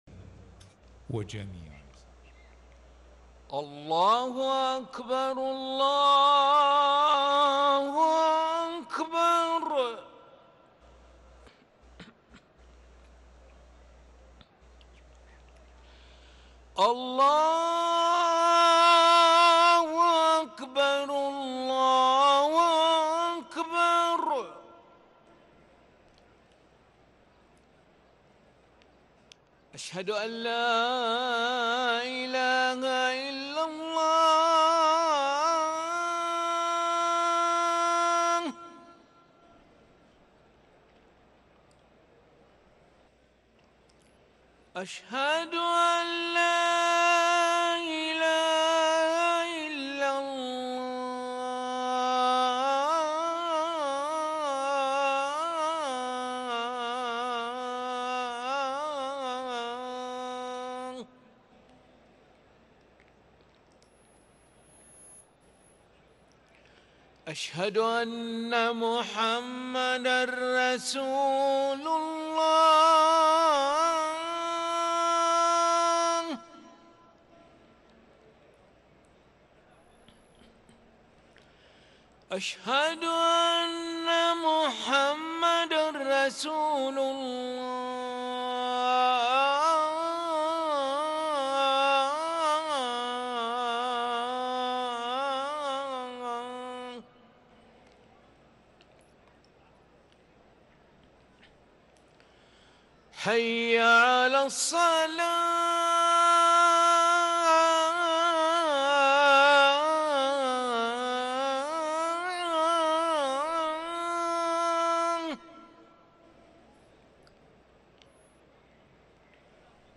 أذان العشاء للمؤذن علي ملا الخميس 24 ربيع الأول 1444هـ > ١٤٤٤ 🕋 > ركن الأذان 🕋 > المزيد - تلاوات الحرمين